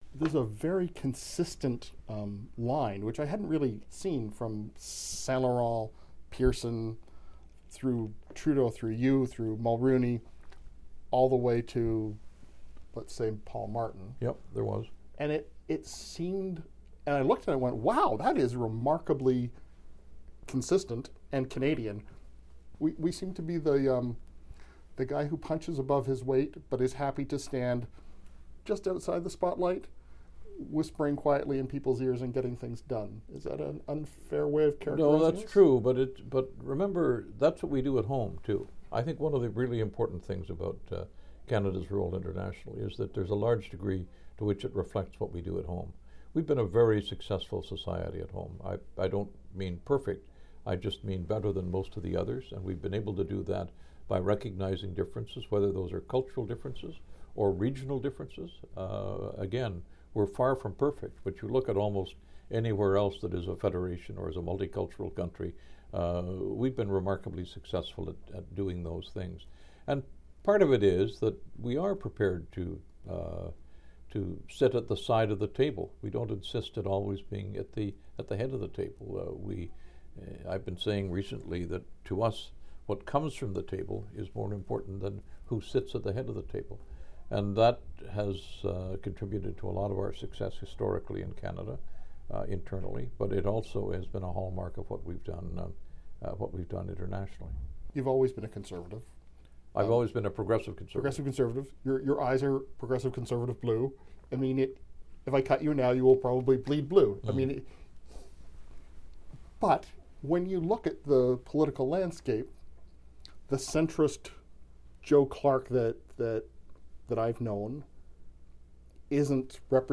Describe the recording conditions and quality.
Recording Location: Toronto 128kbps Stereo